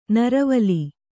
♪ naravali